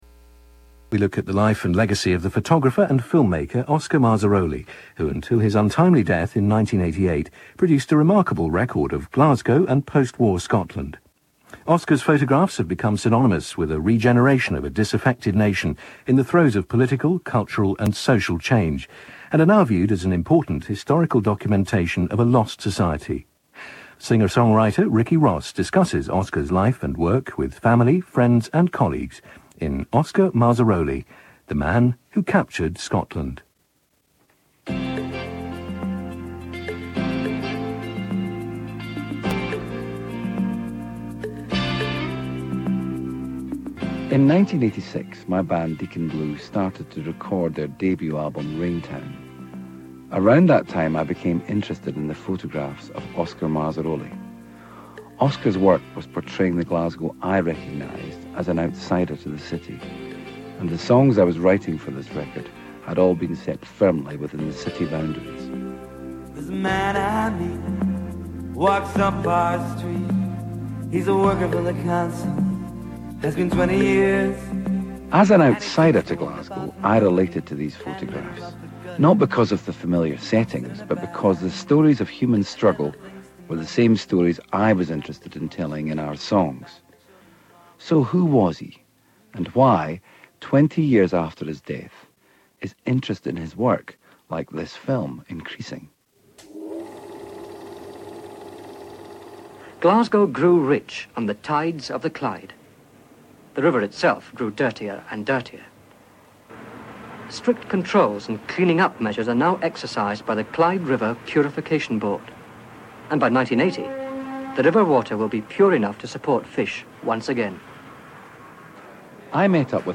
In this BBC program Scottish singer-songwriter Ricky Ross examines the life and work of photographer and film-maker Oscar Marzaroli. Born in Italy in 1933, Marzaroli moved to Glasgow at the age of two. Photographing in black and white, he produced a remarkable record of post-Second World War Scotland, and became famed for his iconic images of the Gorbals in the 1960s.